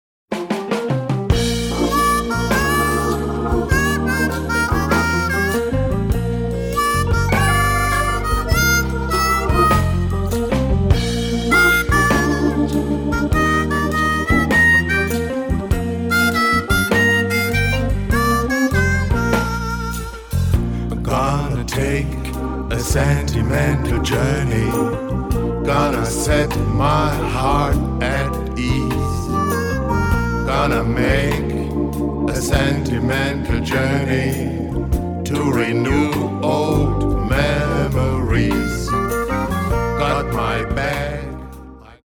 Vocals
Saxes
Piano
Guitar
Bass
Percussion
Drums